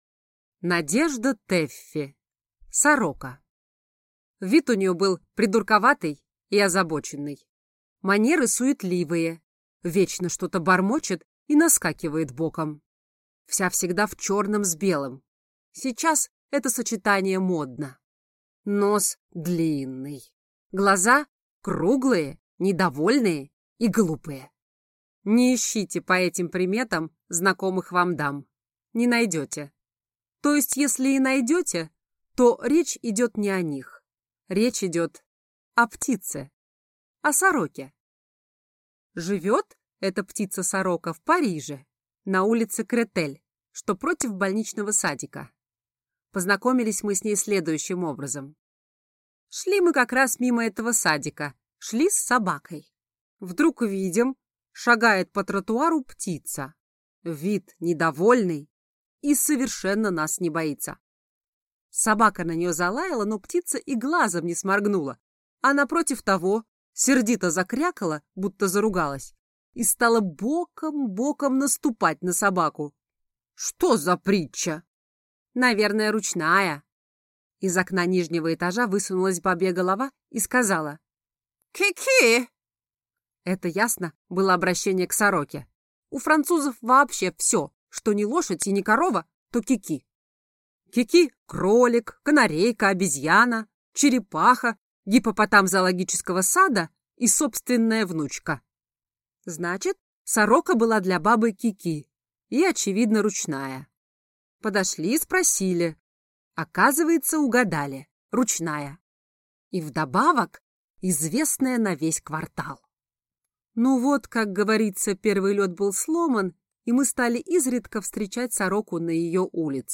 Aудиокнига Сорока